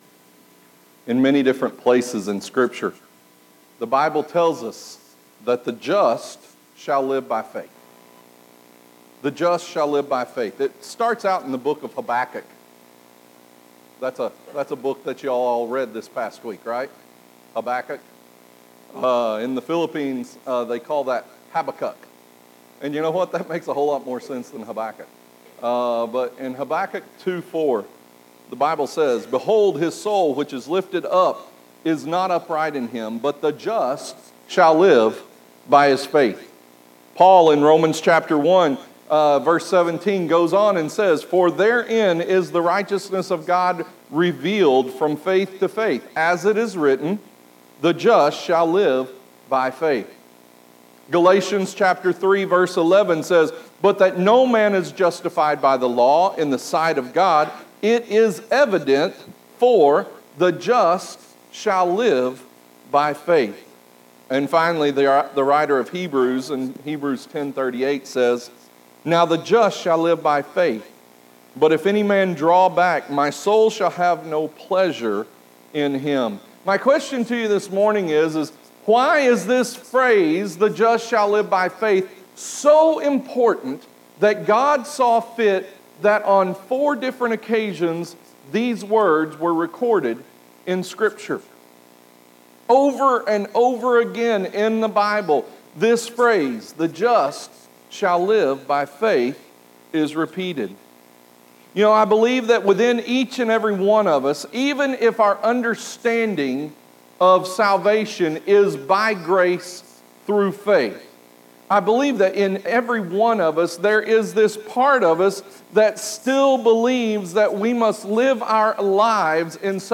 Growing by Faith through the Spirit – Sermon 8